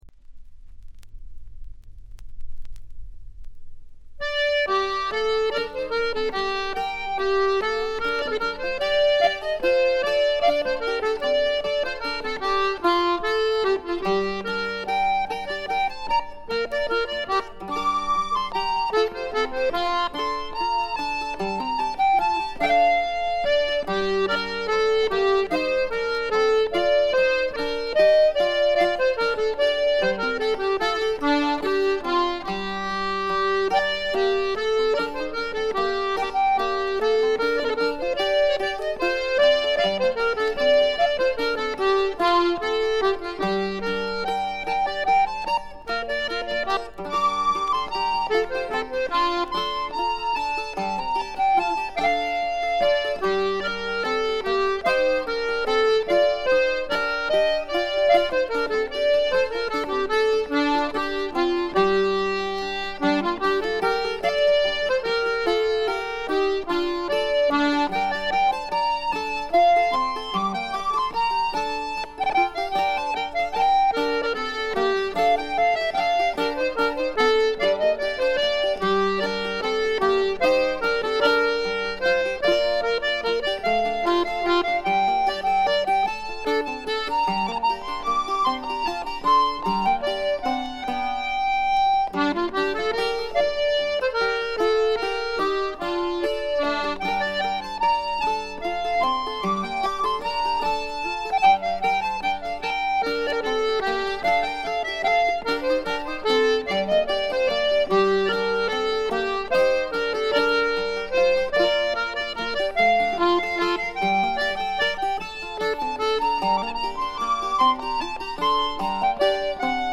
ごくわずかなノイズ感のみ。
売りである哀愁のアイリッシュムードもばっちり。
アイリッシュ・トラッド基本盤。
試聴曲は現品からの取り込み音源です。